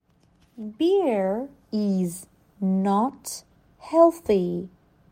جدول کلمات، جمله ها و معنی آن به همراه تلفظ با سه سرعت مختلف:
تلفظ با سرعت‌های مختلف